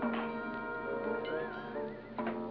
boing